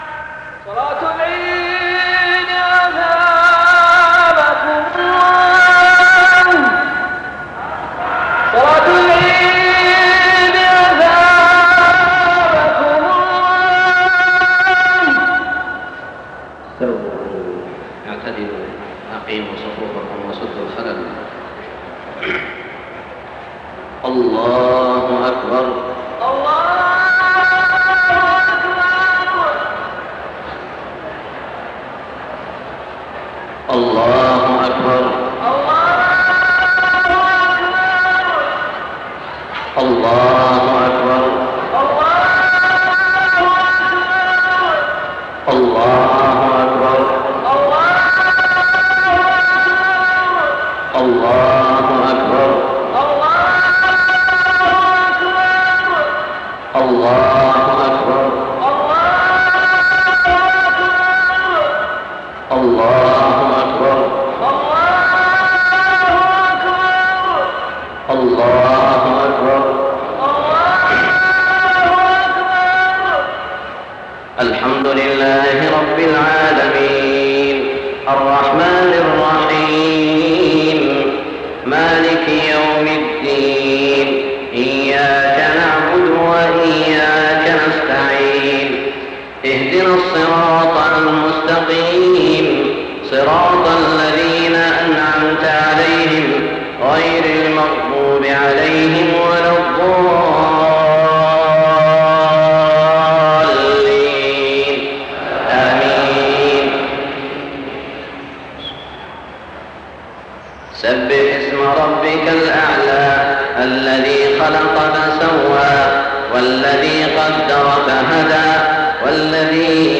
صلاة عيد الأضحى 1422هـ سورتي الأعلى و الغاشية > 1422 🕋 > الفروض - تلاوات الحرمين